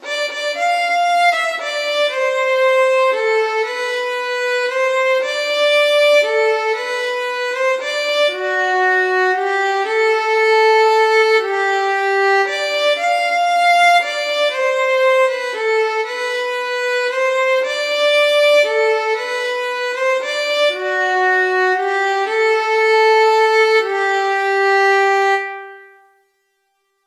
8_mockbeggar_30174_st2_ll.5_8_northernnancy_major_fiddle.mp3 (848.16 KB)
Audio fiddle of transcribed recording of stanza 2, lines 5–8, of the first “Mock-Beggar Hall” ballad, sung to “Northern Nancy” (major)